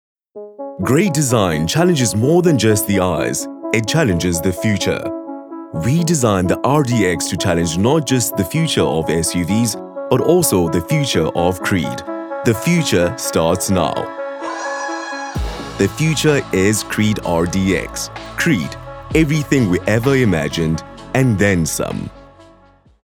cultured, elegant, polished, refined
My demo reels